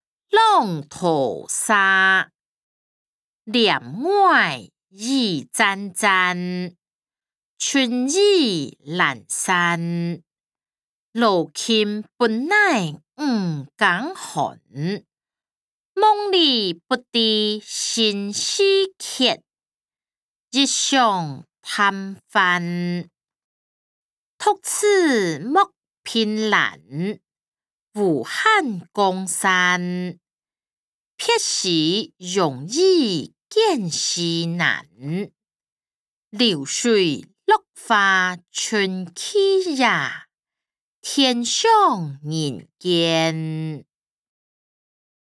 詞、曲-浪淘沙音檔(大埔腔)